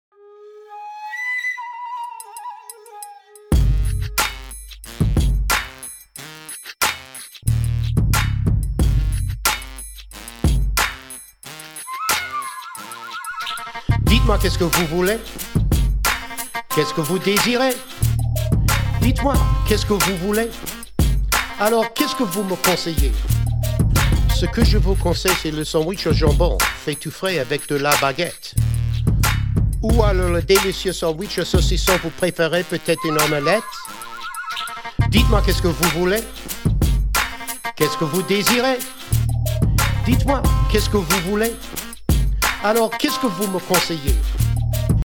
French Language Raps
Ten original raps on CD.